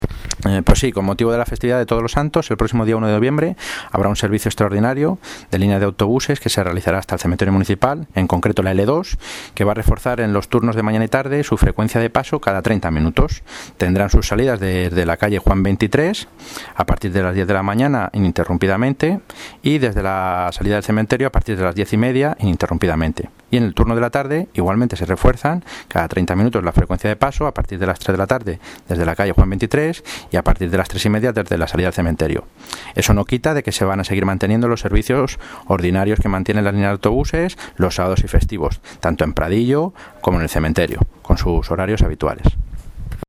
Audio - Roberto Sánchez (Concejal Seguridad Ciudadana y Movilidad) sobre autobuses todos los santos